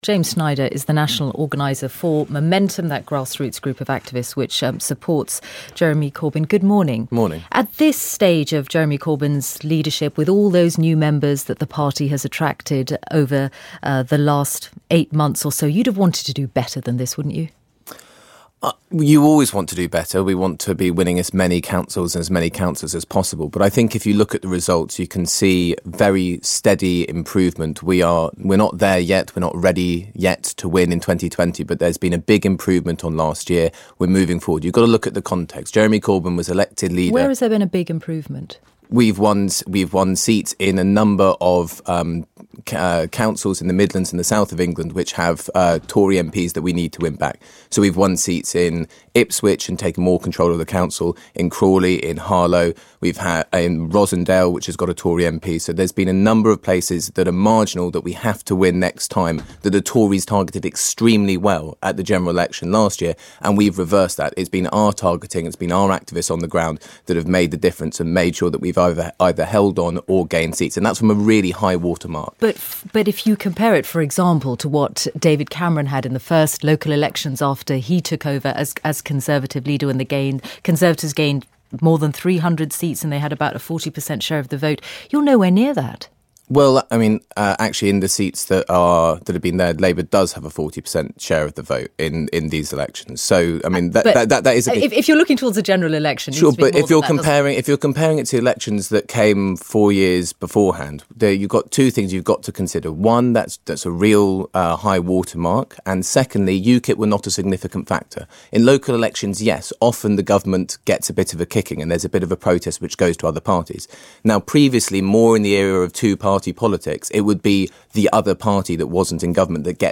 BBC Radio 4 Today Program segment